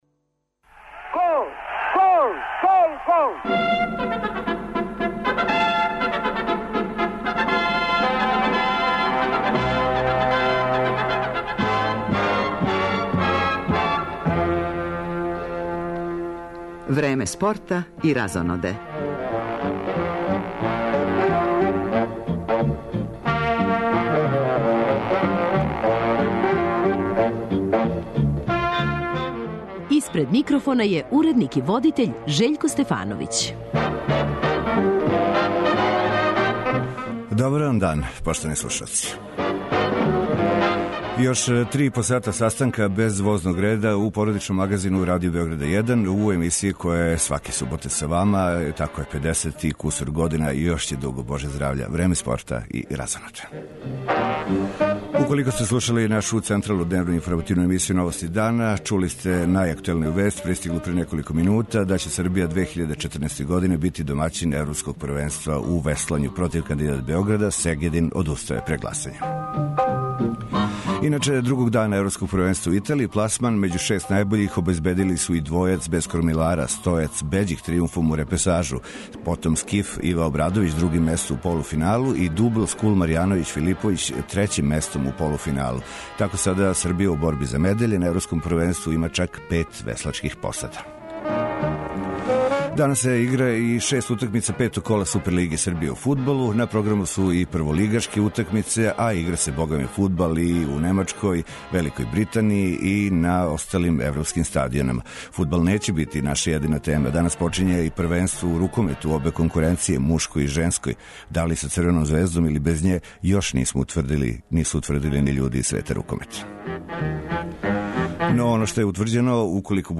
У студију је и прослављени кошаркашки ас Жарко Варајић,коме је у ратном вихору нестало свих 56 освојених медаља у каријери, а једну од њих недавно је добио назад,потпуно неочекивано.